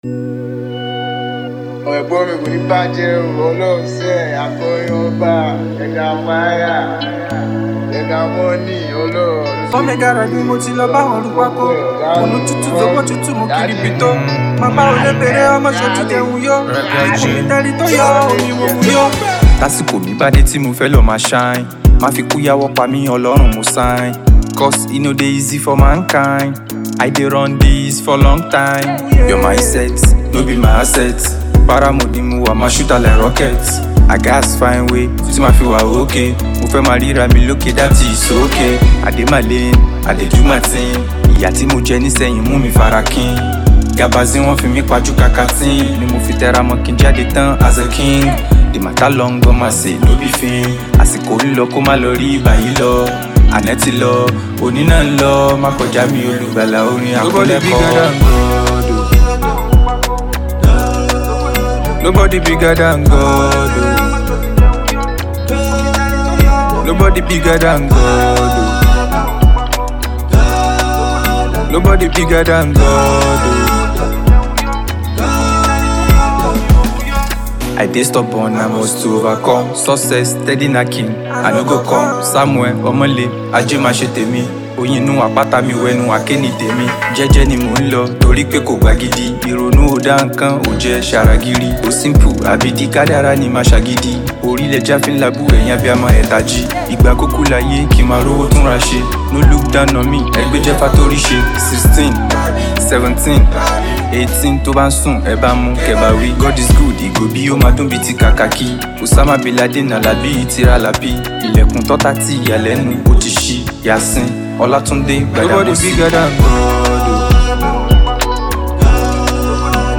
Afrobeat
creating the perfect anthem for every dance floor.